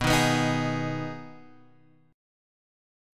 B6 Chord